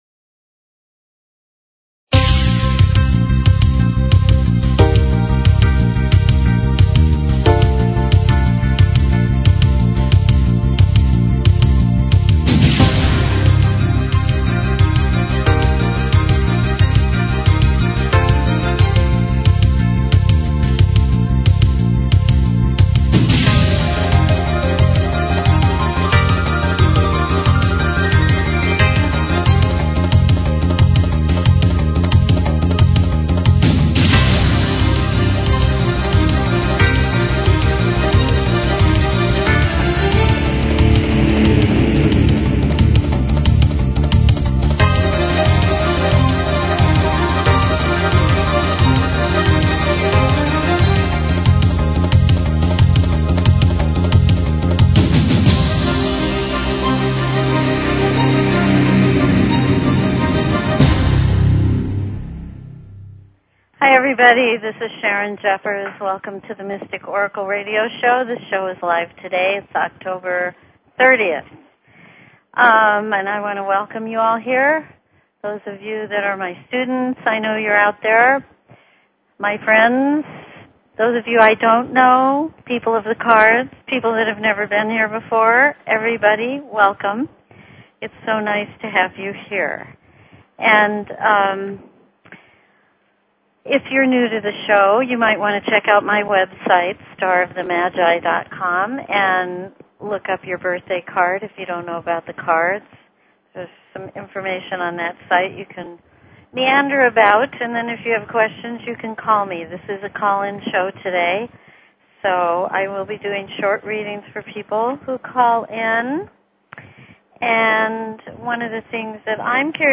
Talk Show Episode, Audio Podcast, The_Mystic_Oracle and Courtesy of BBS Radio on , show guests , about , categorized as
Open lines for calls.